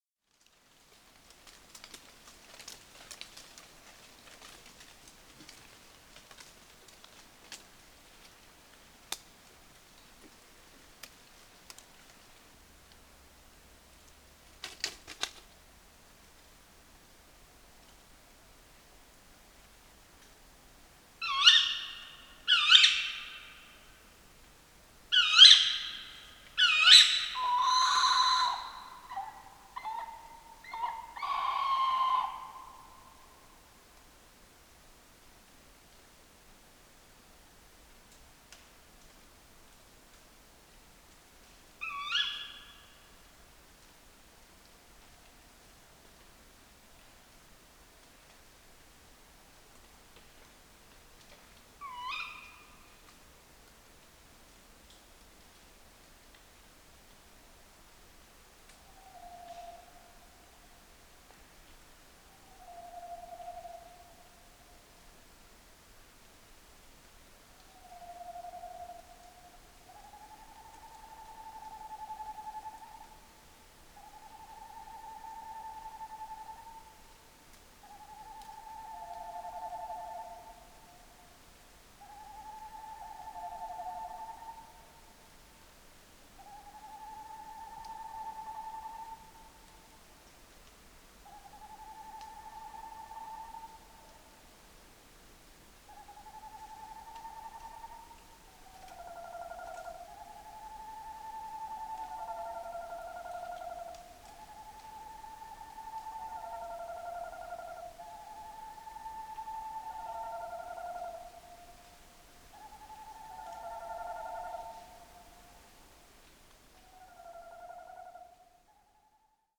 Includes specially-compiled 60 minute CD of field recordings from the Gruenrekorder label.
16 Waldkauz-Balz